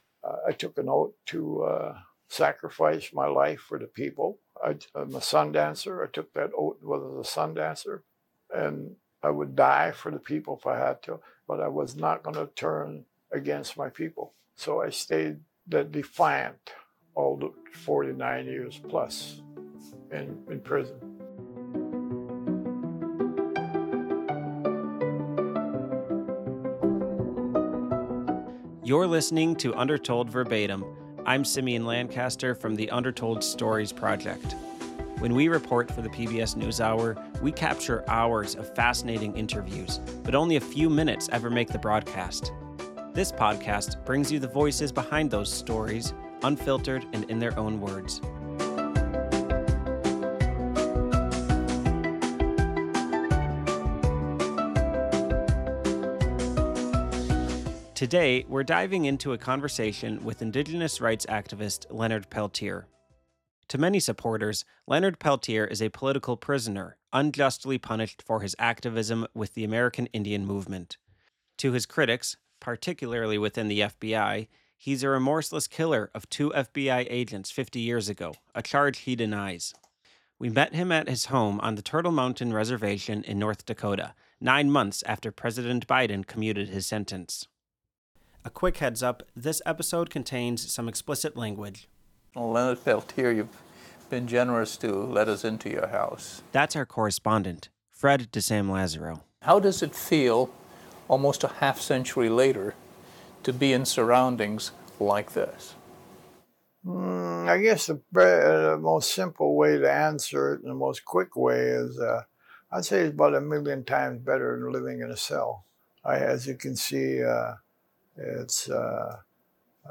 Now, at 81, and after 49 years in prison, Peltier lives under home confinement on North Dakota’s Turtle Mountain Reservation. We visited him there for a rare, candid conversation about his past, his anger, his activism, and the cause that still drives him.